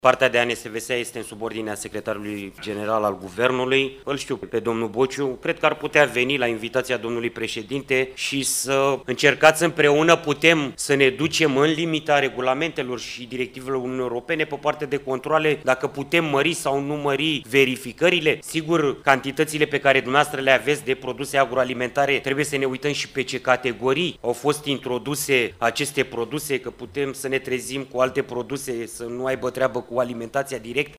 Ministrul Agriculturii, Florin Barbu, a spus că măsuri ar putea fi luate pentru a depista produsele cu probleme.